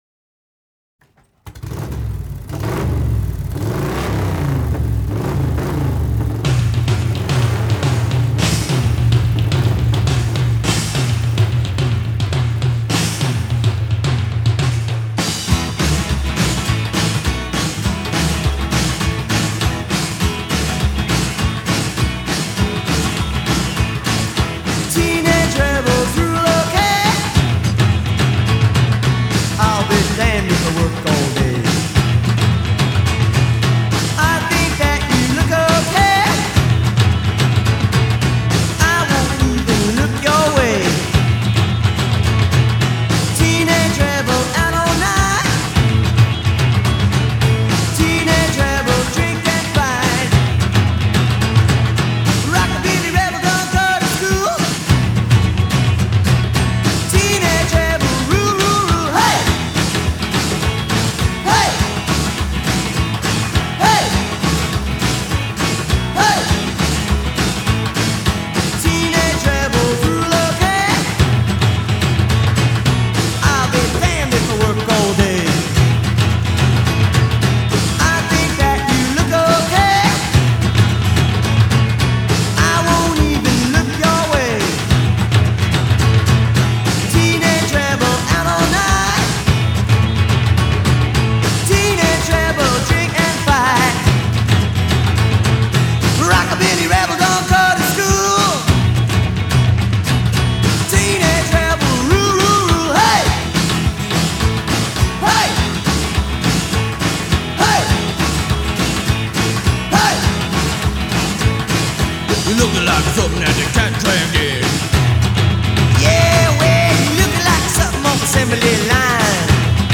Heart-warming, familiar rumble at the beginning for me.